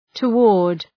Προφορά
{tə’wɔ:rd}